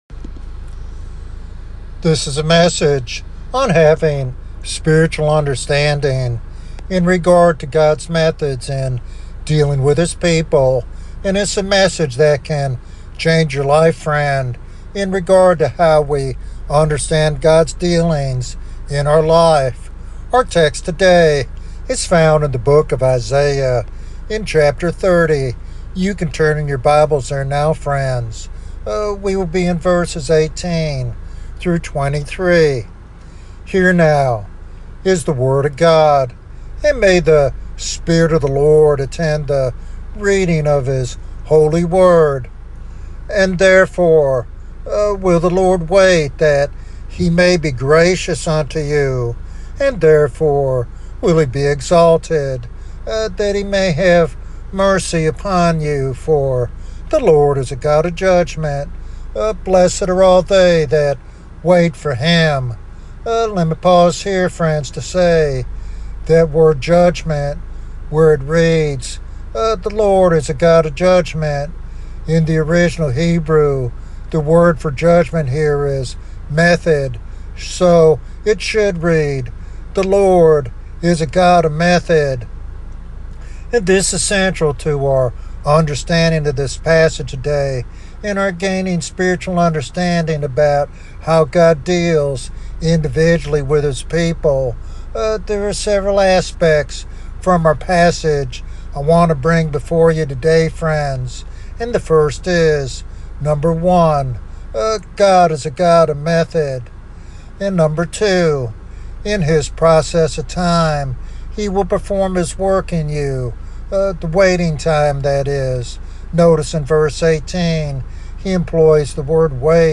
This teaching provides practical wisdom for navigating life's challenges with a divine perspective.